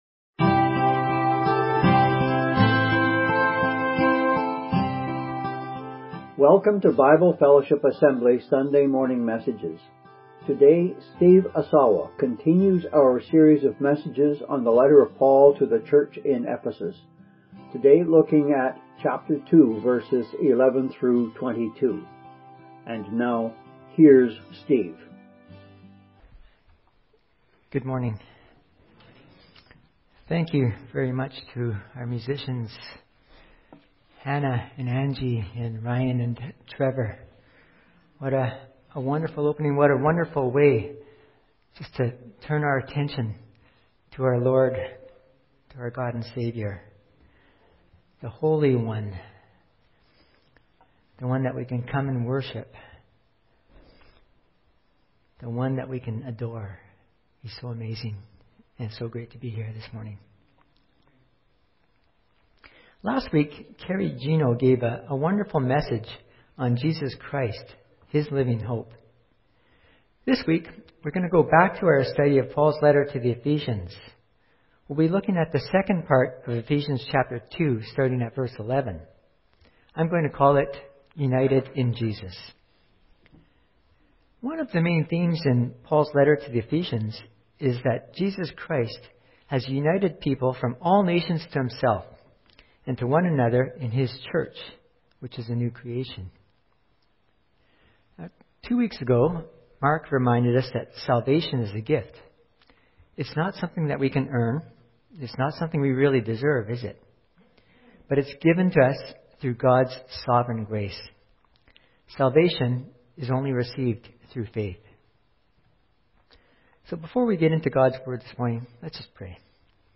Lyssna till Matthew 15:1-20 - Heart Of The Problem/Problem Of The Heart och 479 mer episoder från Bible Fellowship Assembly Sunday Morning Messages gratis!